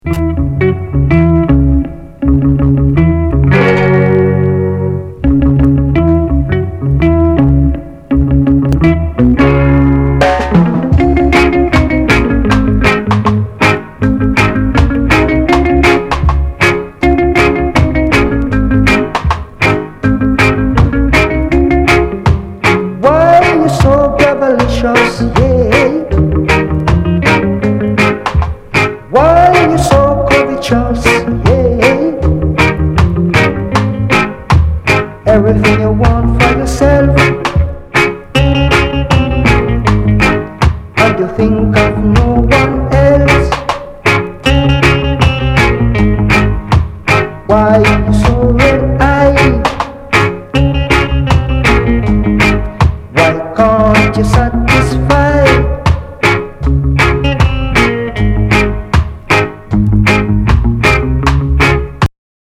REGGAE & DUB
ルーツ・レゲエやダンスホールの黄金期のサウンドが詰まった1枚！